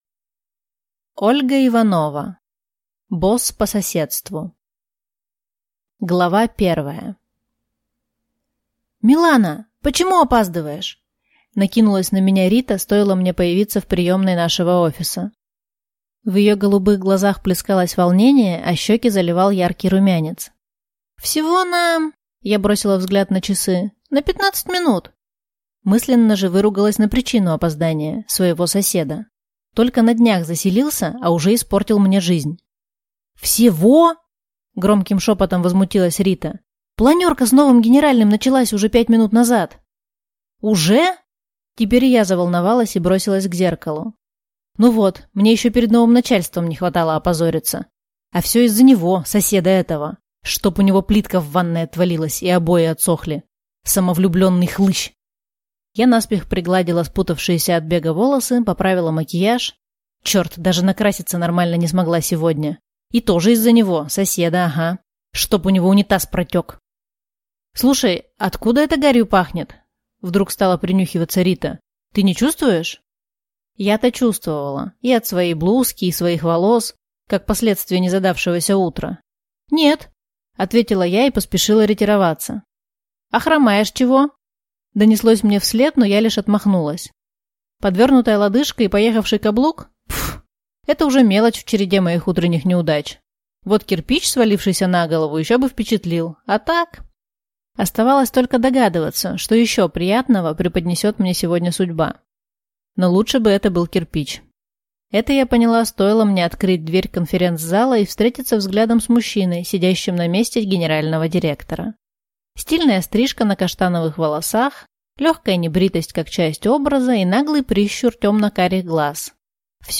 Аудиокнига Босс по соседству | Библиотека аудиокниг